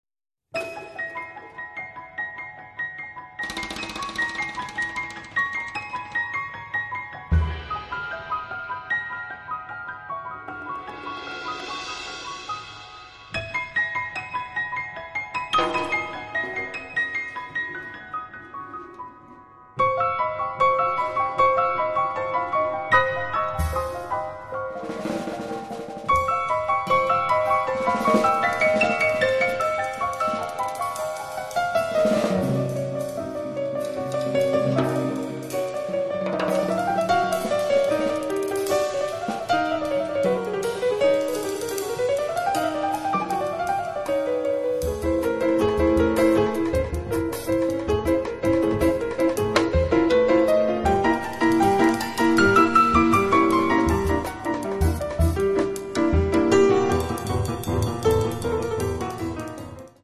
pianoforte, sintetizzatore, samplers, live electronics
contrabbasso, basso elettrico
batteria, percussioni